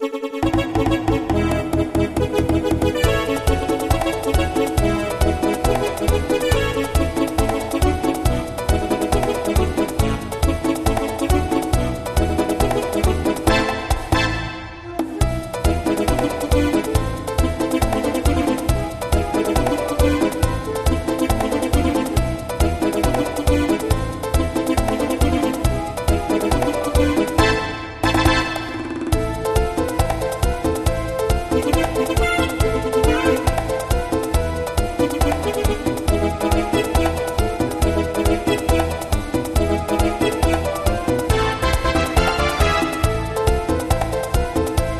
midi/karaoke